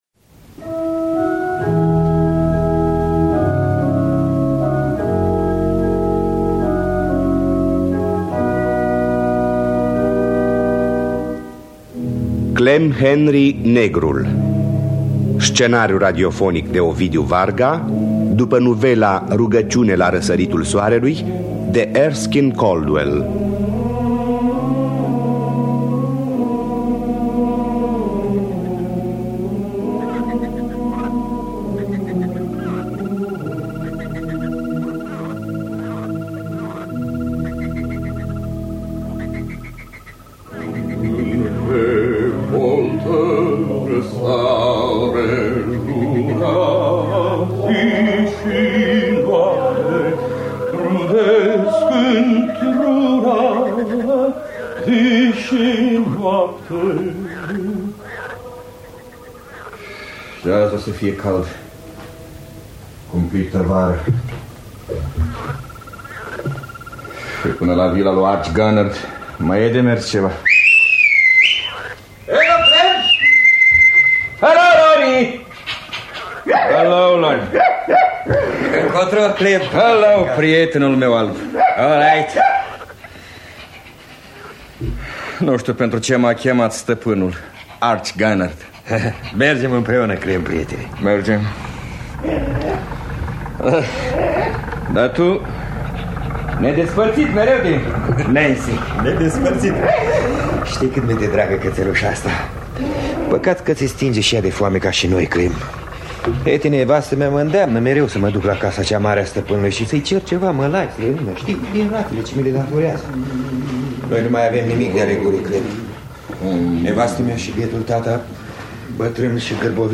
Clem Henry, negrul sau Rugăciune la răsăritul soarelui de Erskine Preston Caldwell – Teatru Radiofonic Online